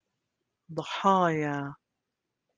Jordanian